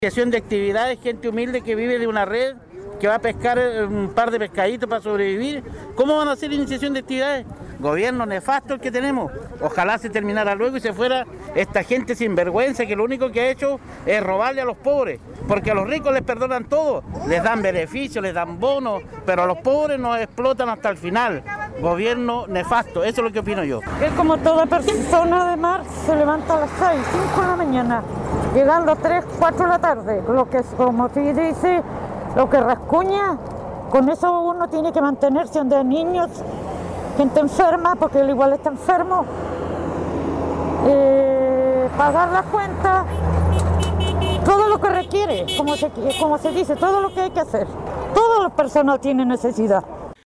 Durante la jornada de protesta se escucharon varias voces de los participantes de la toma del acceso al puente Pudeto, dando a conocer su mirada acerca de la crisis que vive el sector de la Pesca Artesanal de la zona.